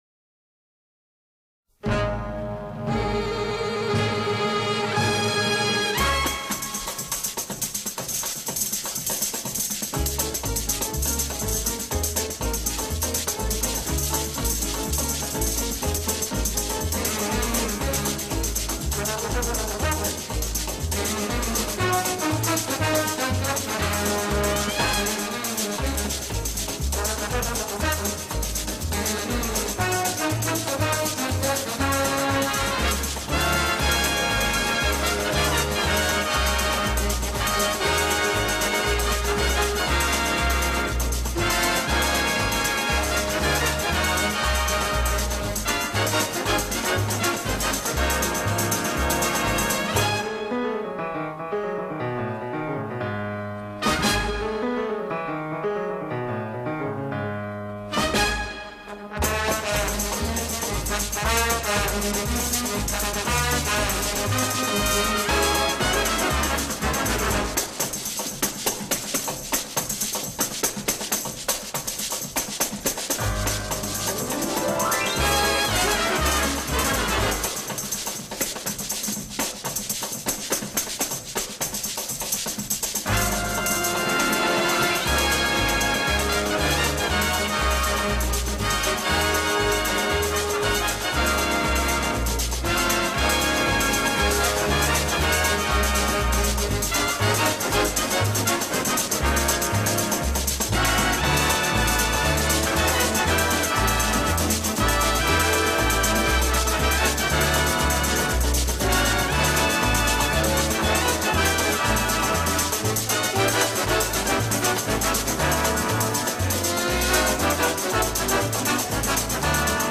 Жанр: Instrumental, Classical, Easy Listening